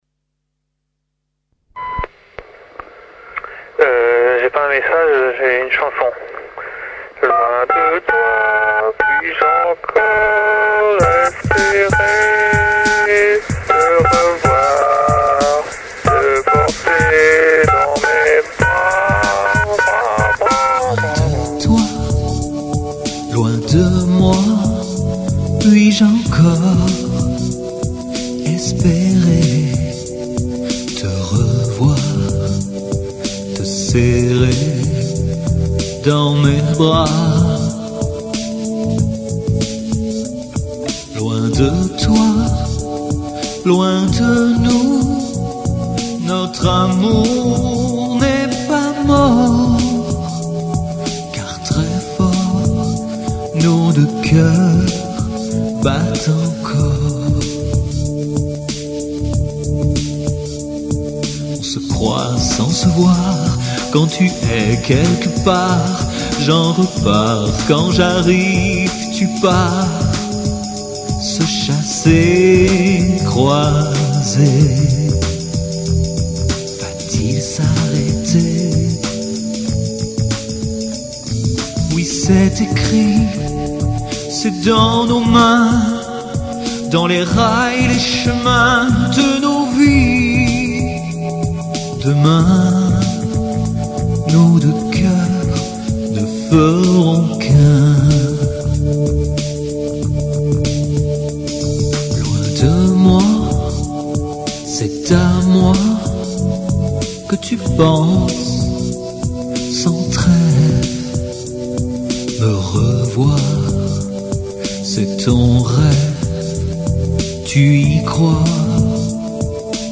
LE groupe rennais mythique de mes débuts, gentillement déjanté, aux chansons d'amour « fleur bleue » et aux soli interminables, composé d'étudiants ratés, d'un conducteur de train et d'un ingénieur chimiste ! Première maquette enregistrée sur un « quatre pistes » à cassettes dans une salle de classe d'école maternelle …On est resté amis et, aujourd'hui, nos messages sur nos répondeurs inspirent parfois de curieuses